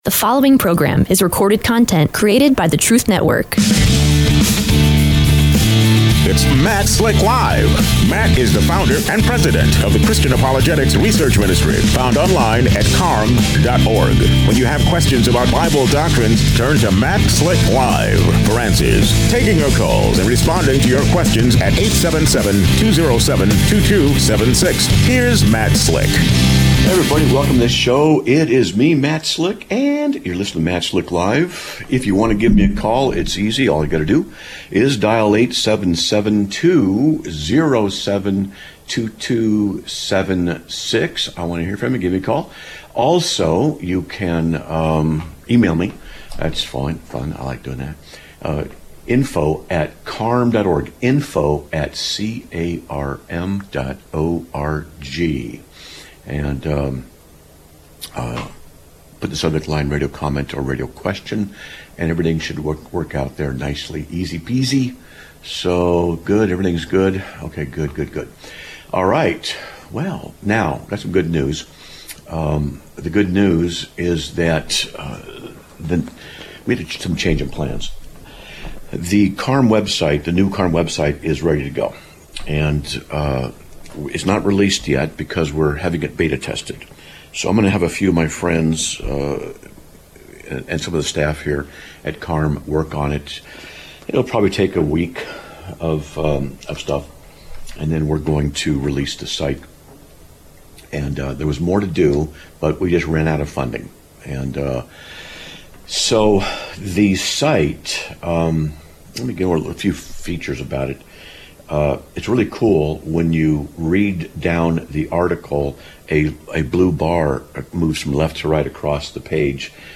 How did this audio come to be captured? Live Broadcast of 08/29/2025) is a production of the Christian Apologetics Research Ministry (CARM).